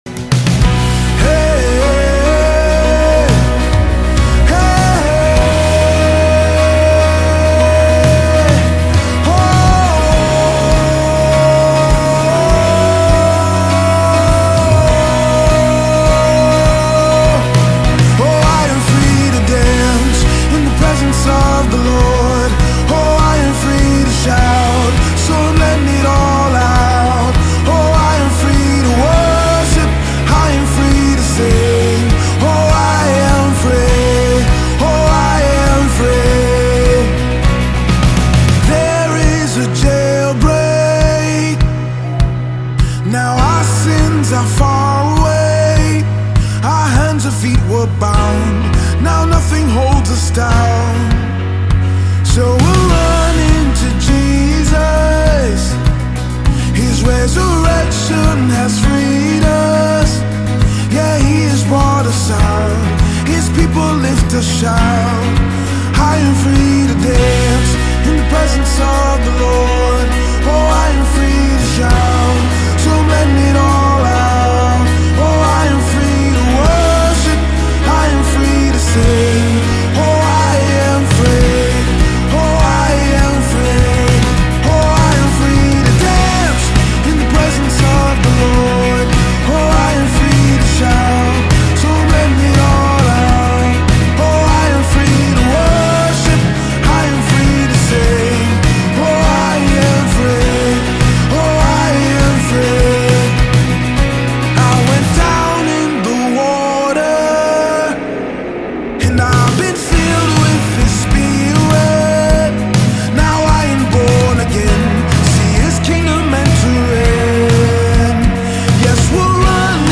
2025 single